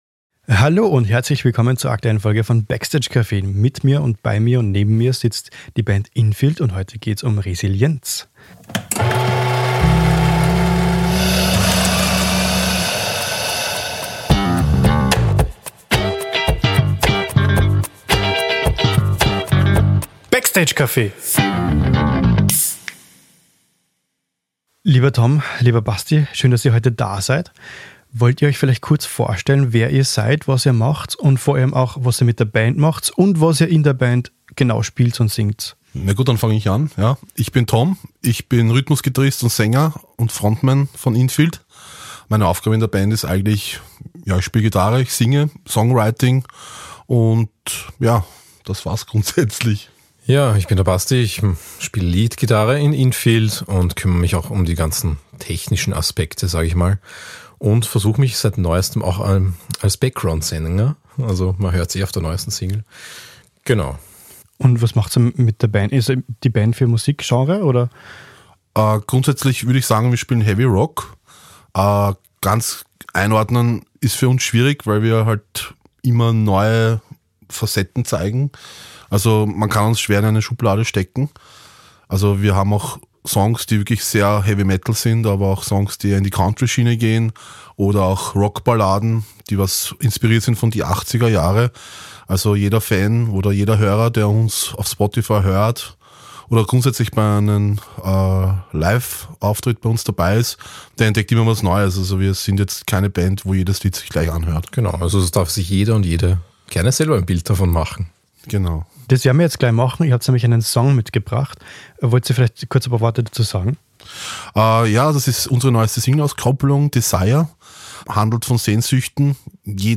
Offen und ehrlich erzählen die Bandmitglieder, wie sie mit Rückschlägen umgegangen sind, was ihnen geholfen hat, dranzubleiben, und warum es manchmal genau diese schwierigen Phasen sind, die eine Band stärker machen. Ein ehrlicher Blick hinter die Kulissen – über Zweifel, Zusammenhalt und die Leidenschaft, die immer wieder neu entfacht wird.